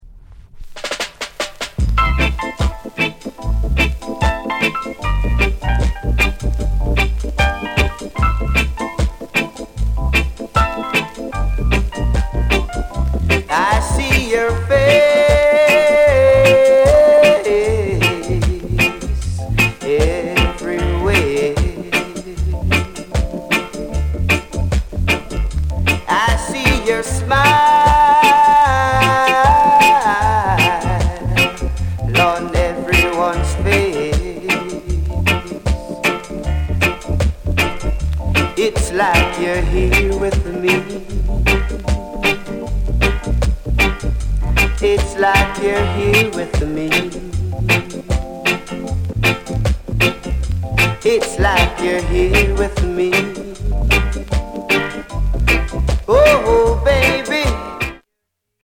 NICE VOCAL